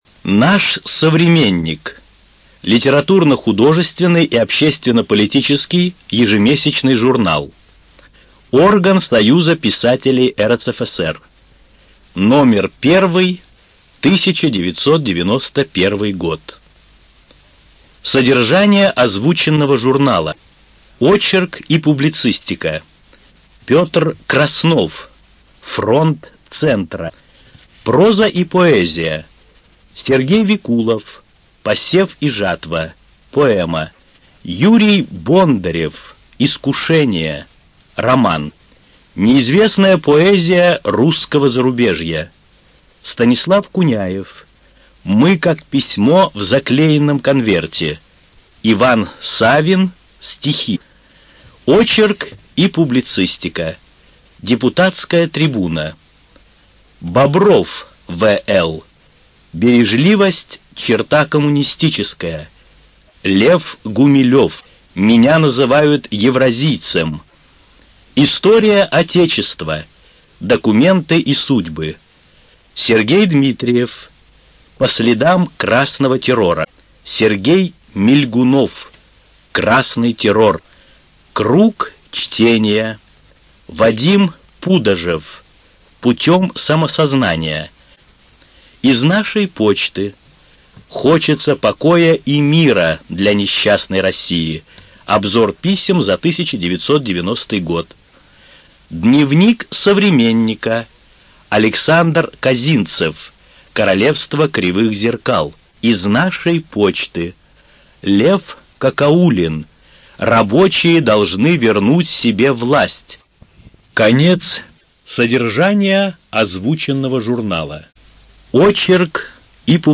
Студия звукозаписиКругозор